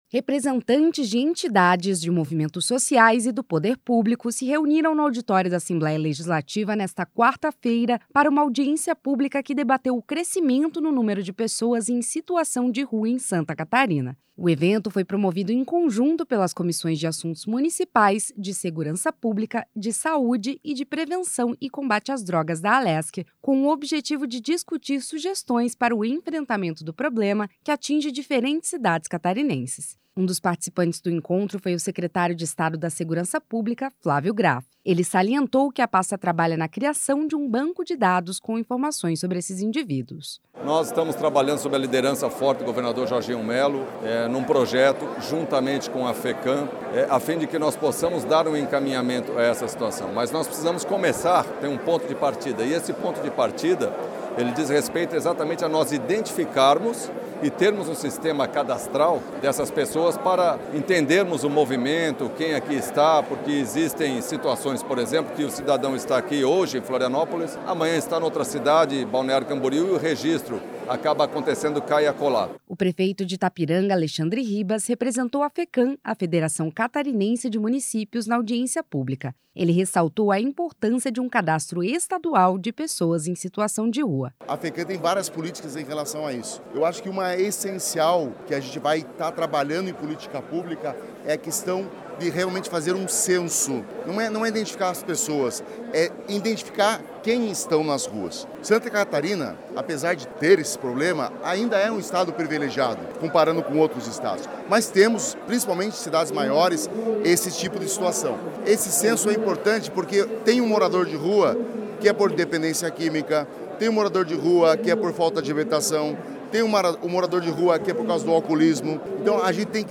Representantes de entidades, de movimentos sociais e do Poder Público se reuniram no auditório da Assembleia Legislativa, nesta quarta-feira (12), para uma audiência pública que debateu o crescimento no número de pessoas em situação de rua em Santa Catarina.
Entrevistas com:
- Flávio Graff, secretário de Estado da Segurança Pública;